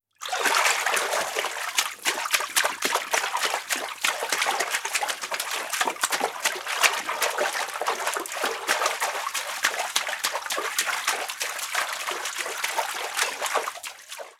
Movimientos de alguien ahogándose en una charca